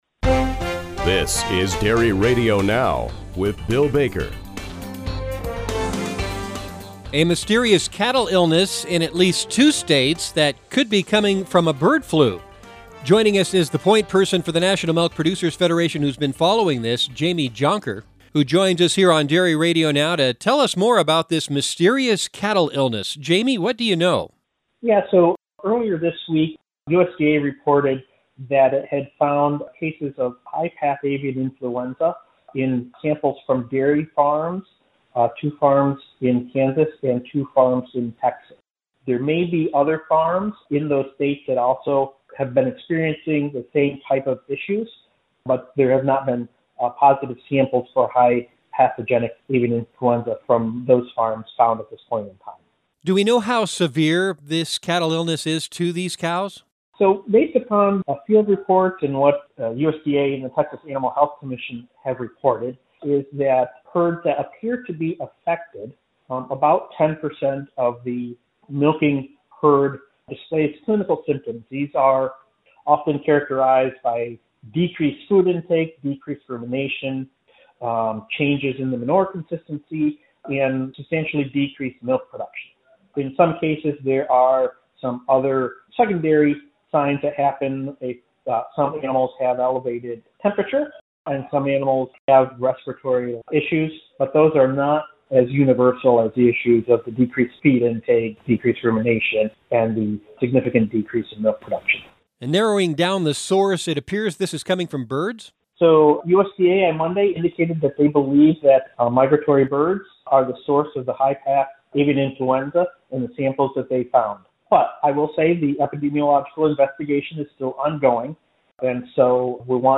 explains to Dairy Radio Now listeners what the implications are of the discovery that bird flu has infected some cattle in Texas and Kansas